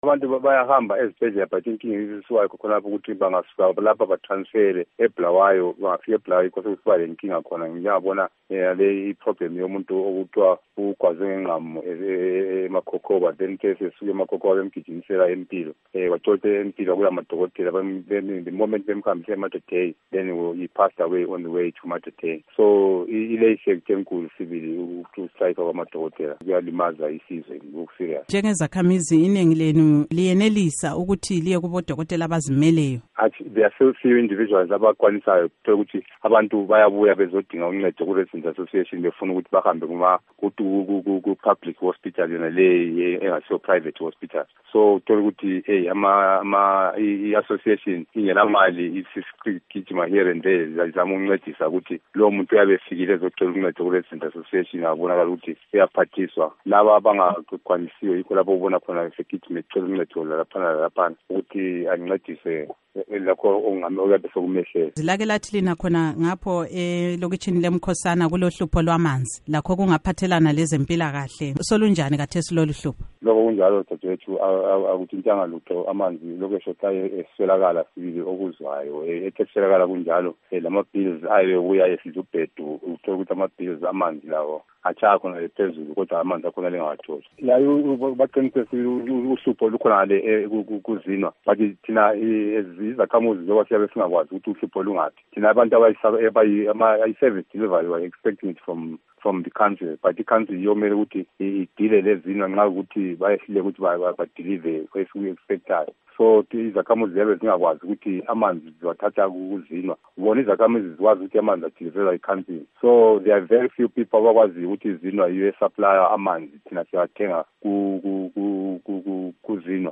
Ingxoxo Lesakhamuzi SeVictoria Falls